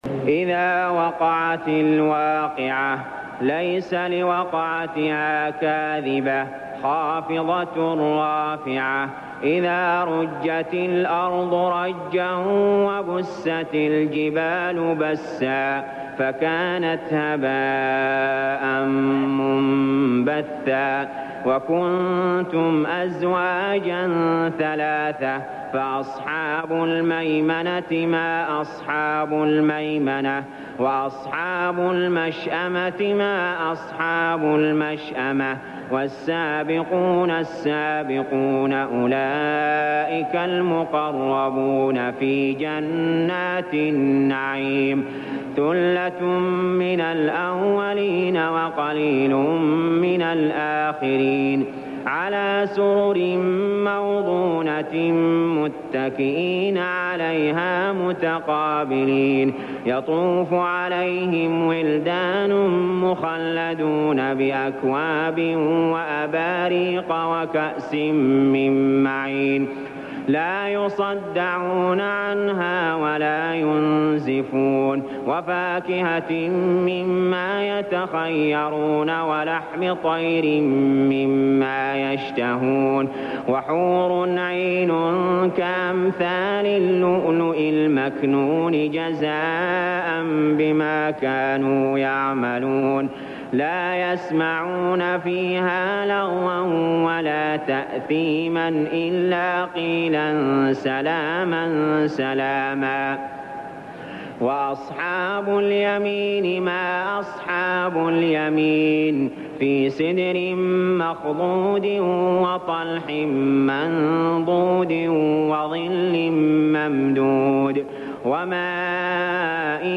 المكان: المسجد الحرام الشيخ: علي جابر رحمه الله علي جابر رحمه الله الواقعة